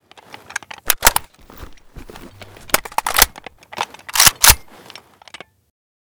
ak74m_reload_empty.ogg